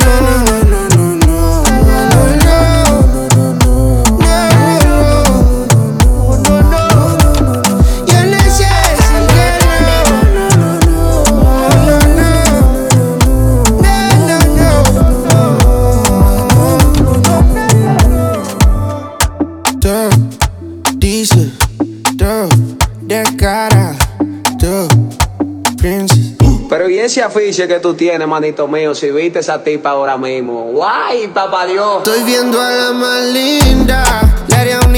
Urbano latino Latin African Afro-Pop
Жанр: Поп музыка / Латино